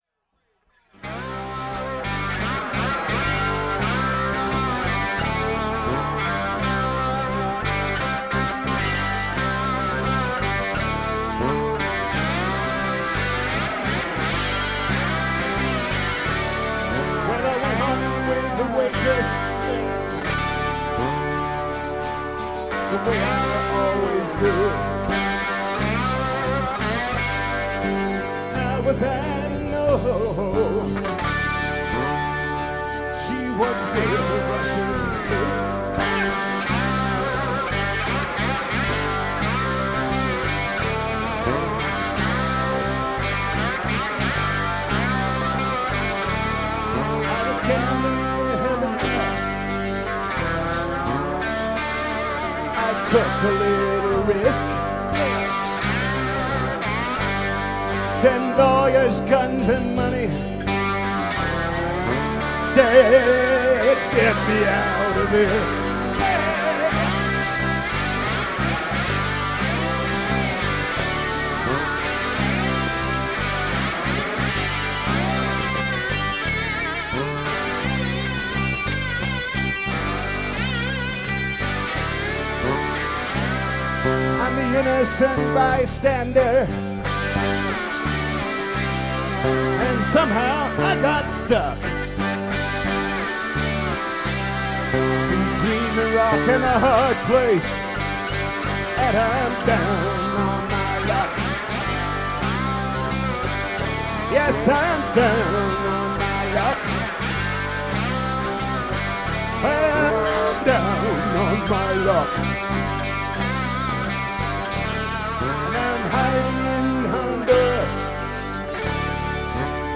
guitar
pedal steel